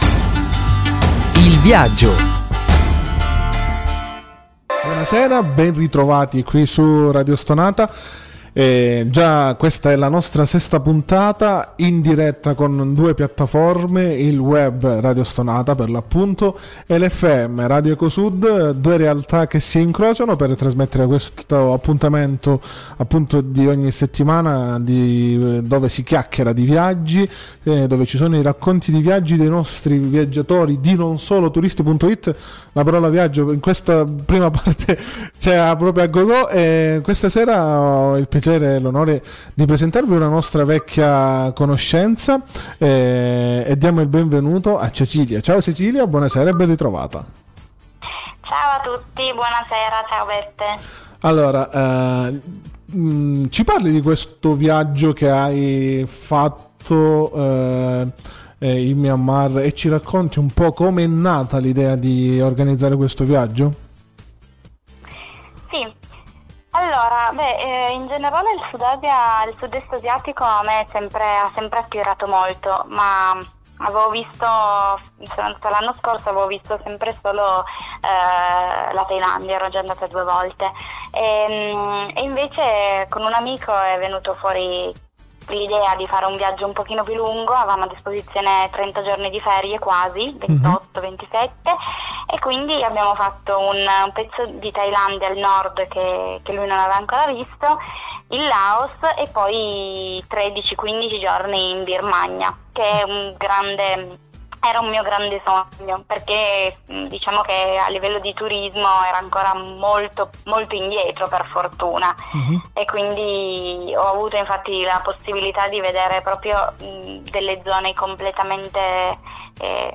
Venerdì 13 marzo sono stata ospite su Radio Stonata, per un intervento all’interno della rubrica “Il viaggio”.